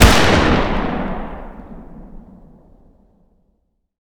fire-dist-44mag-pistol-ext-01.ogg